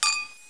00071_Sound_clink.mp3